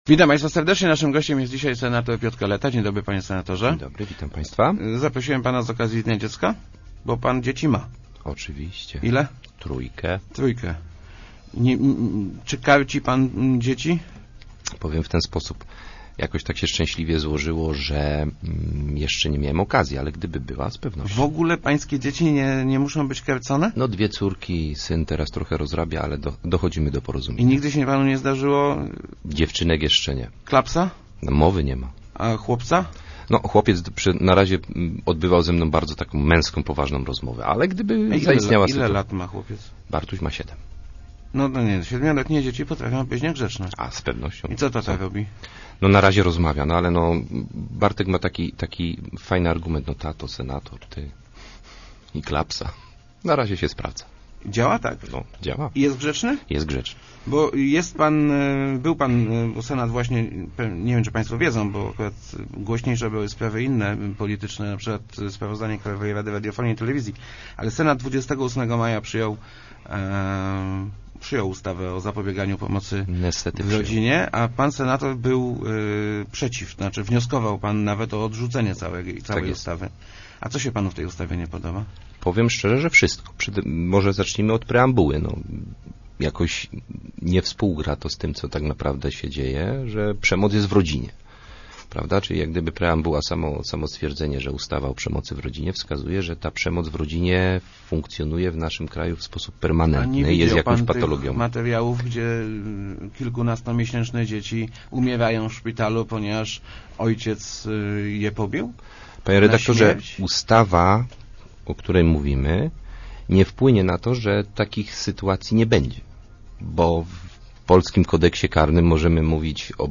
To z�e i niepotrzebne prawo – mówi� w Rozmowach Elki senator PiS Piotr Kaleta, komentuj�c przyj�t� przez Senat ustaw� o zapobieganiu przemocy w rodzinie. Zdaniem parlamentarzysty ustawa da pole do nadu�y�, na przyk�ad poprzez mo�liwo�� odbierania dzieci rodzicom przez pracowników socjalnych.